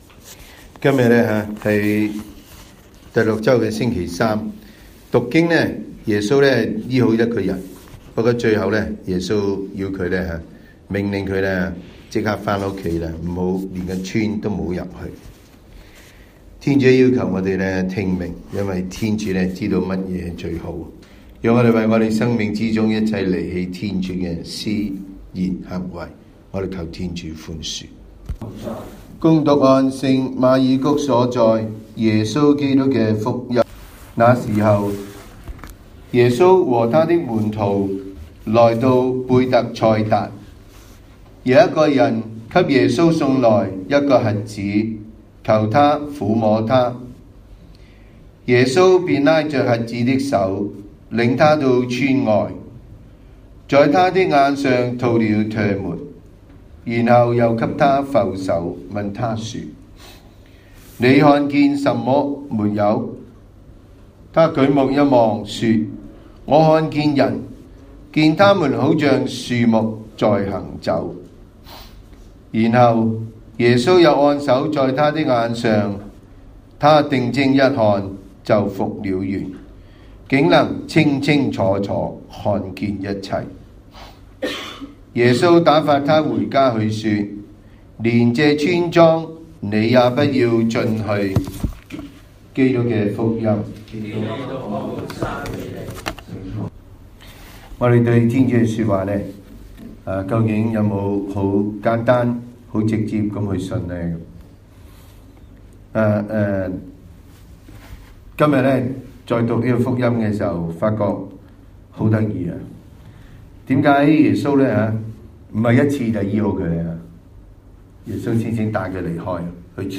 SDB 每日講道及靈修講座